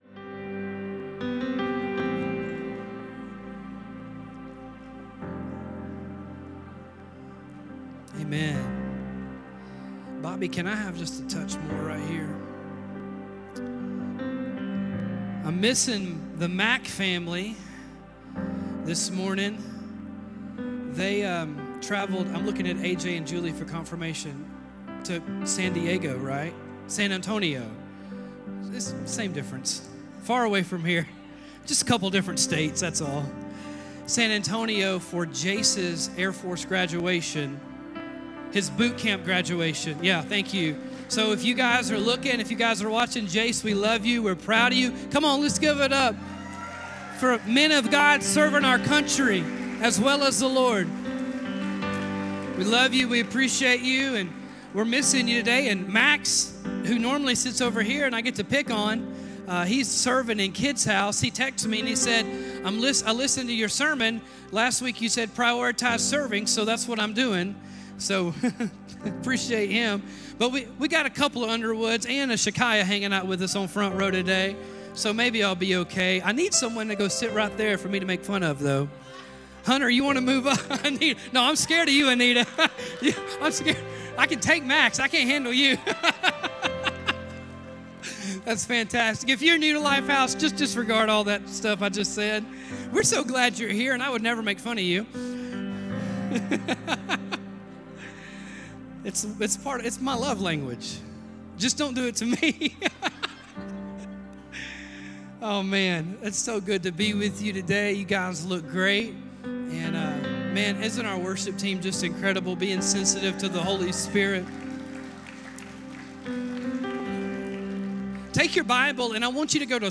We continue the "Life of David" series this week with a message titled "Handling the Holy". The message is taken from 2 Samuel chapter 6. We learn to recognize the significance of God's presence, respect God's presence, rejoice in the privilege of God's presence, and to remain bold in God's presence.